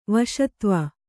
♪ vaśatva